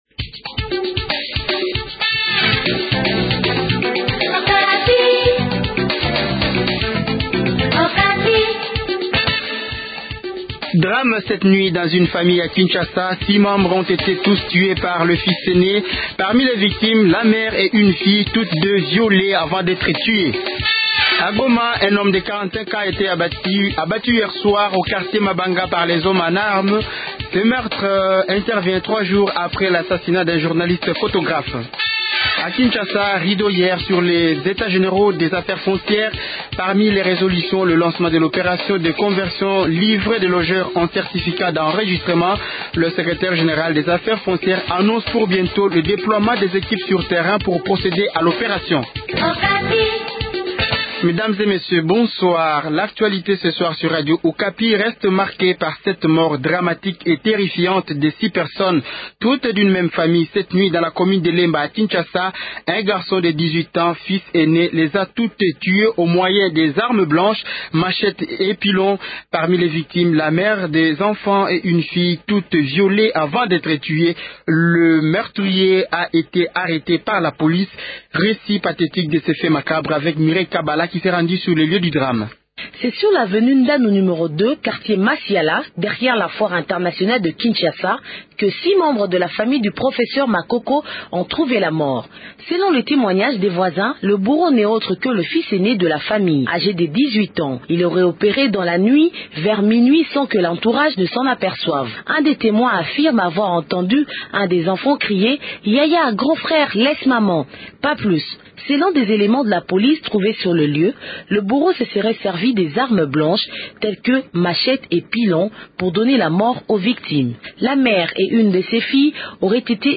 Journal Français Soir 18h